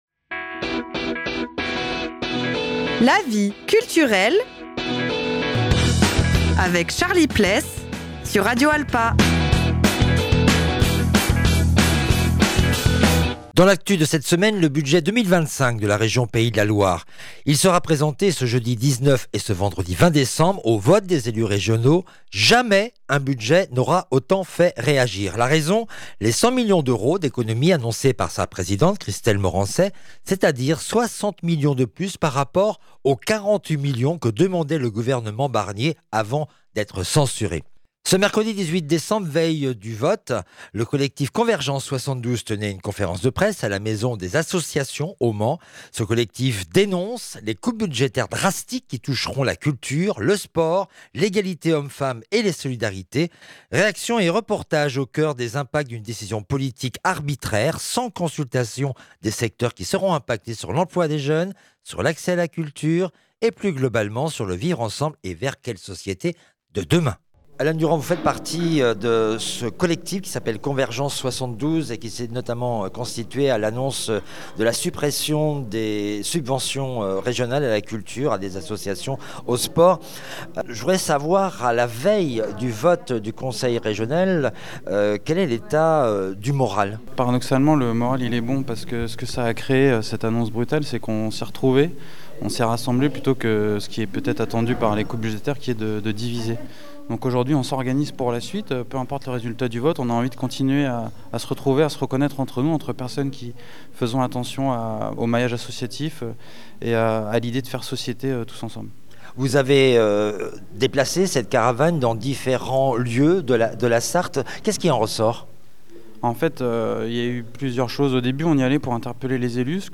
Ce mercredi 18 décembre, veille du vote, le collectif Convergences 72 tenait une conférence de presse à la Maison des associations au Mans. Ce collectif dénonce les coupes budgétaires drastiques qui toucheront la culture, le sport, l’égalité hommes-femmes les solidarités. Réactions et reportage au cœur des impacts d’une décision politique arbitraire sans consultation des secteurs qui seront impactés sur l’emploi des jeunes, sur l’accès à la culture, et plus globalement sur le vivre ensemble et vers quelle société demain ?